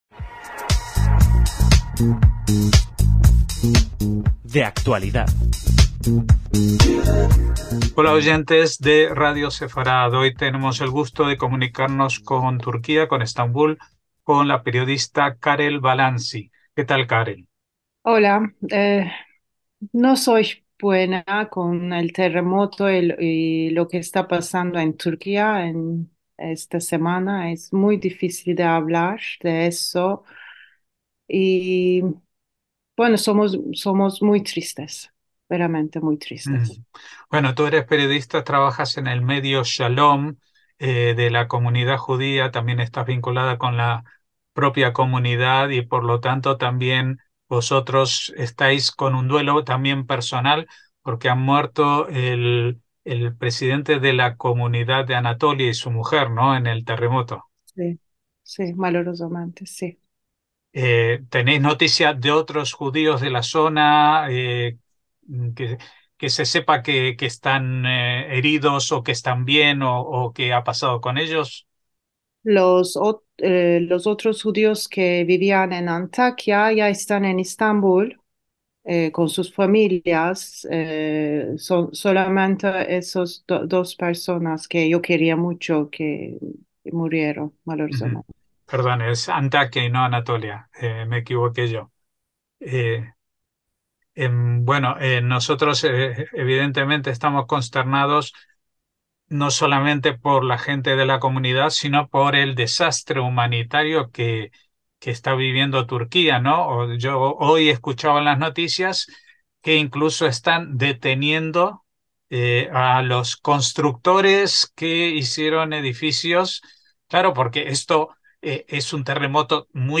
DE ACTUALIDAD - La entrevista